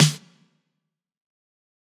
Nothing Compares Snare.wav